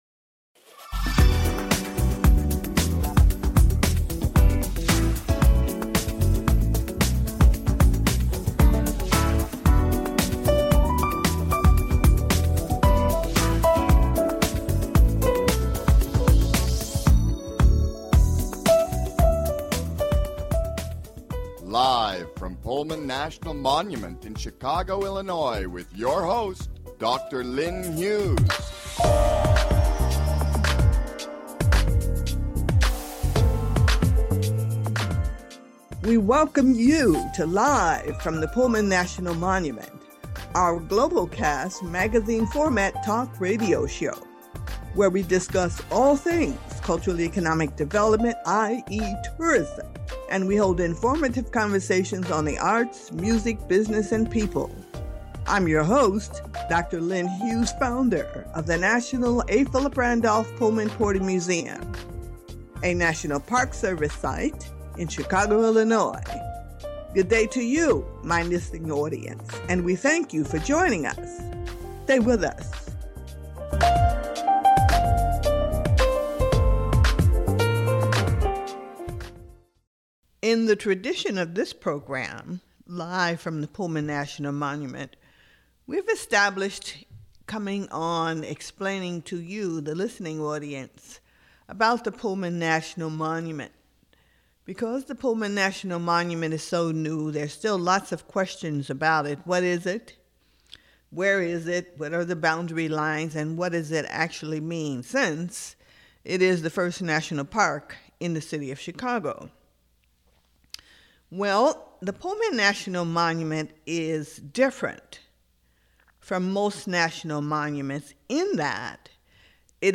This unique global-cast magazine format - Talk Radio Show focuses on all things Cultural Economic Development (CED) Or Tourism!